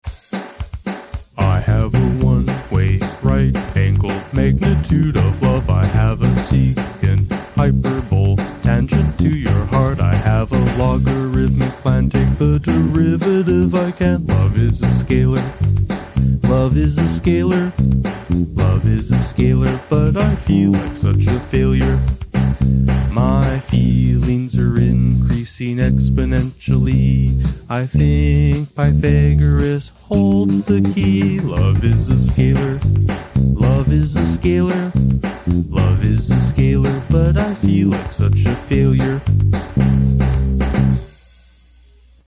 Somewhat interestingly, it has no guitar – just bass and drum machine – and I guess you’d have to classify it as a polka!